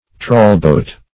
Trawlboat \Trawl"boat`\, n. A boat used in fishing with trawls or trawlnets.